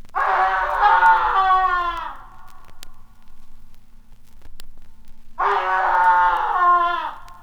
• two screams - male.wav
Recorded from Sound Effects - Death and Horror rare BBC records and tapes vinyl, vol. 13, 1977. Equipment used: TTA05USB akai player and focusrite 8i8 interface, using an SSL limiter for some dimmed s...
two_screams_-_male__i2W.wav